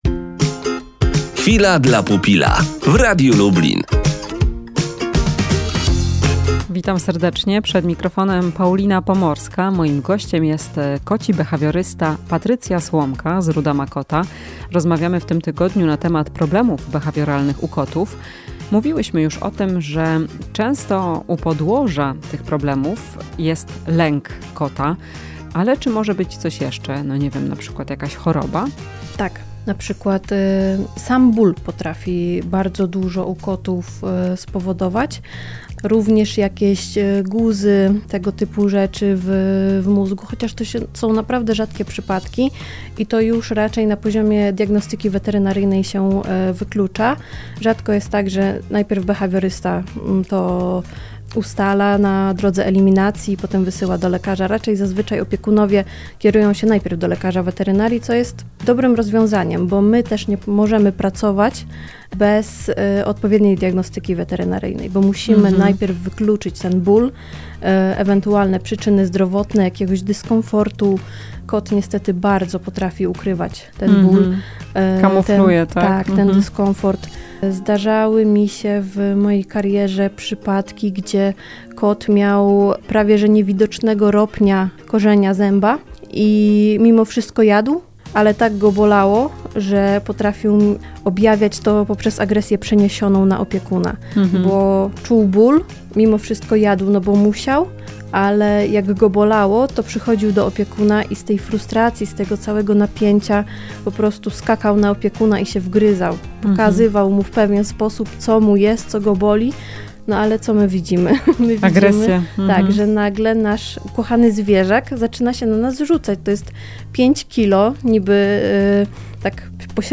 W "Chwili dla pupila" powiemy czy problemy ze zdrowiem mogą przekładać się na problematyczne zachowanie u kota? Rozmowa z behawiorystą kotów, zoodietetykiem